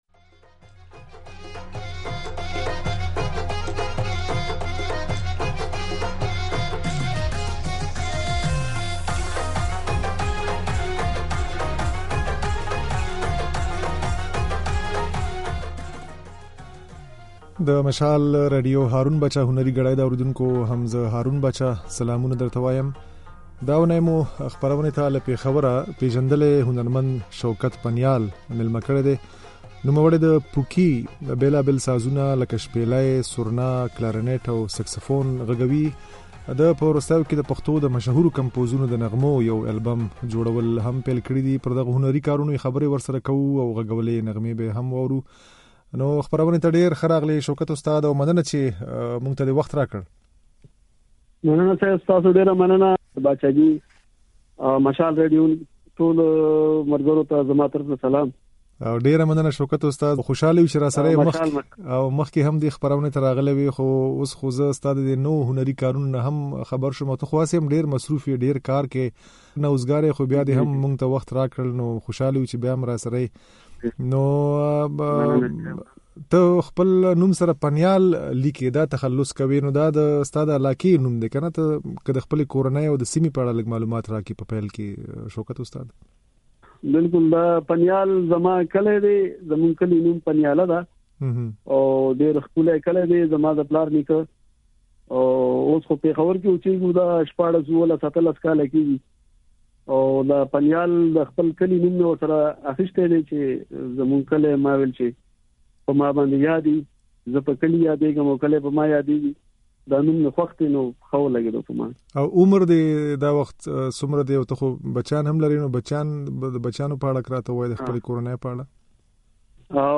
نوموړی کلارنټ او سيکسفون هم غږوي او په وروستيو کې يې د پوکي يو نوی ساز هم ايجاد کړی چې نلوفون نوم يې ورته خوښ کړی دی.